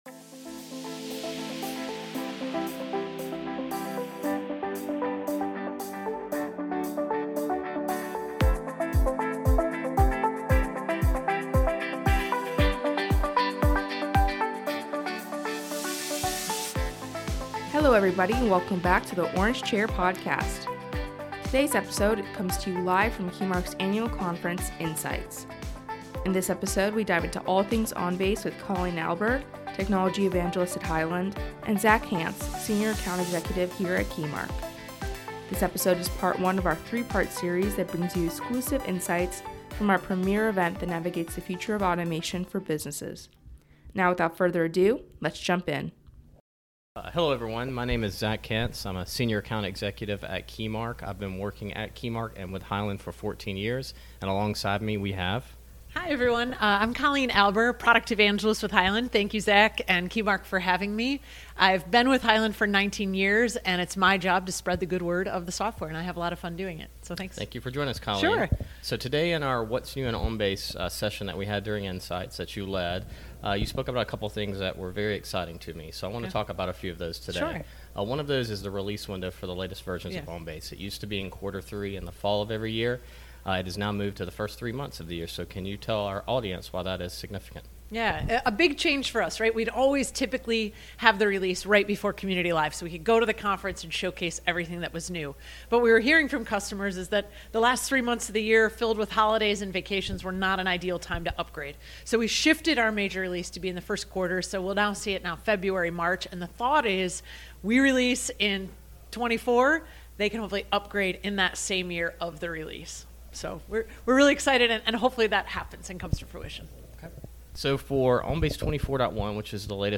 Live From Insights: All Things OnBase
This episode is part one of our three-part series that brings you exclusive insights from our premier event that navigates the future of automation for businesses!